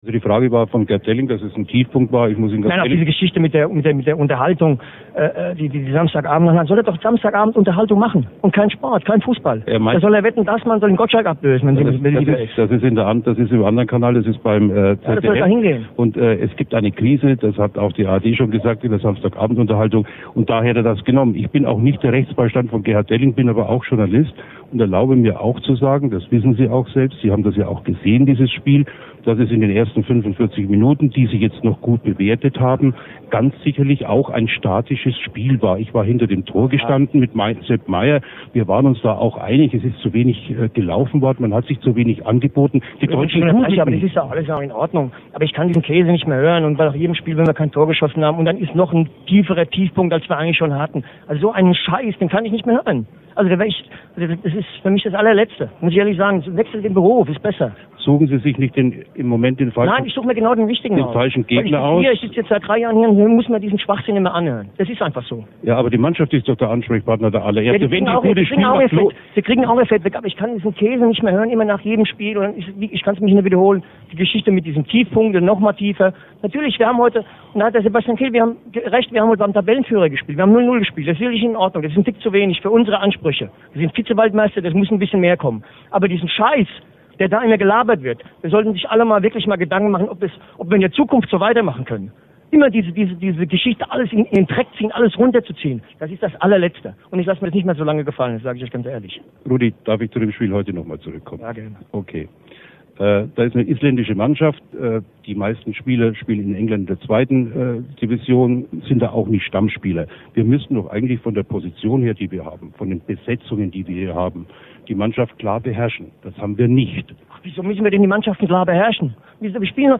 Rudi Völlers Wutausbruch